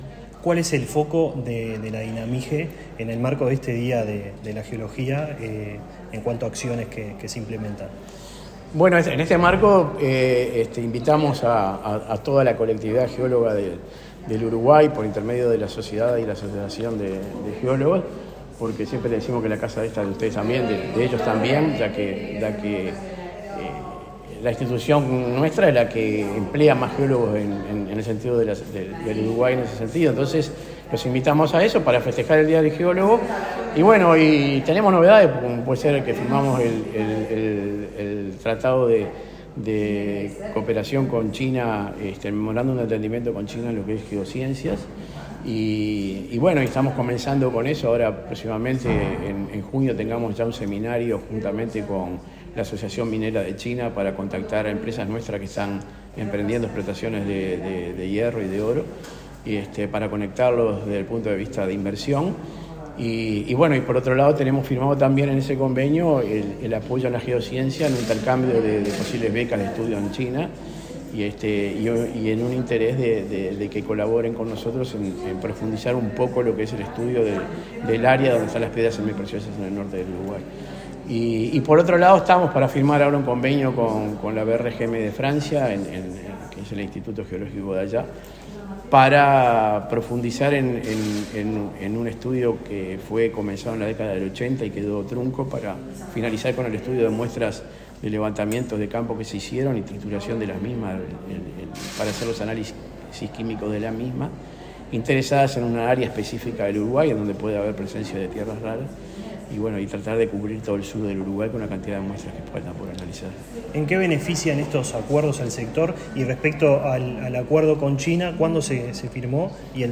Entrevista al direcror de la Dinamige, Marcelo Pugliesi
Entrevista al direcror de la Dinamige, Marcelo Pugliesi 28/05/2024 Compartir Facebook X Copiar enlace WhatsApp LinkedIn La Dirección Nacional de Minería y Geología (Dinamige) de Ministerio de Industria, Energía y Minería (MIEM) realizó, este 28 de mayo, el acto de celebración por el Día del Geólogo. En la oportunidad, el titular de esa dependencia, Marcelo Pugliesi, realizó declaraciones a Comunicación Presidencial.